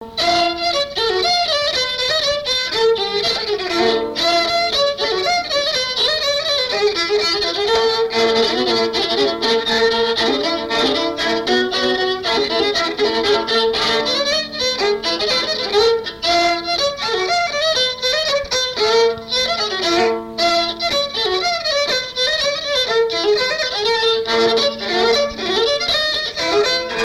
Mémoires et Patrimoines vivants - RaddO est une base de données d'archives iconographiques et sonores.
danse : pas d'été
Pièce musicale inédite